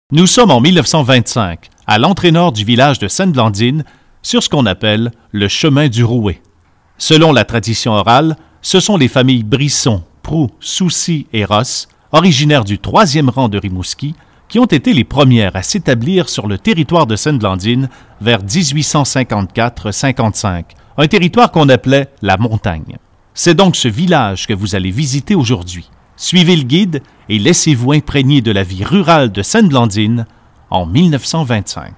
Juin 2011 Audioguide du village de Sainte-Blandine Grâce à une somme de 1000 $, versée par la caisse Desjardins du Haut-Pays de la Neigette, la Maison Souvenance reprend vie!